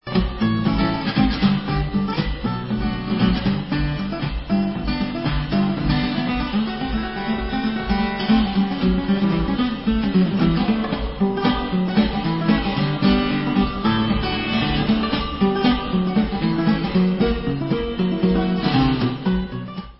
sledovat novinky v oddělení Pop/Symphonic